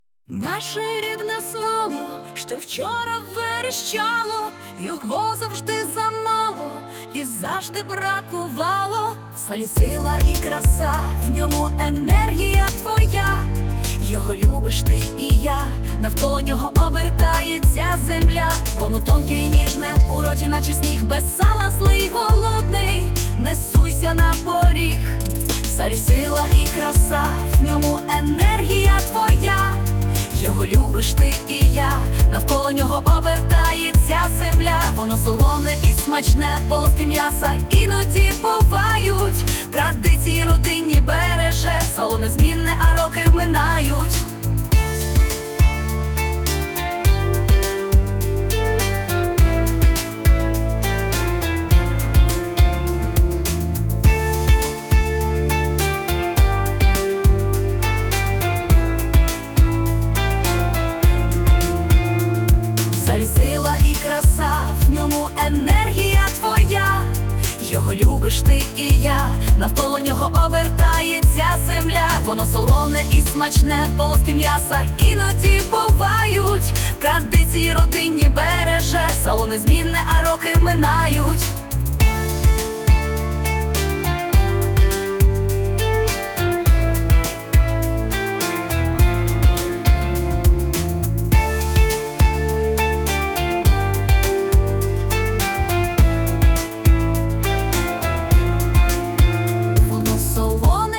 Ukrainian version of the song. The text is my own, the music and vocals are generated by artificial intelligence AI.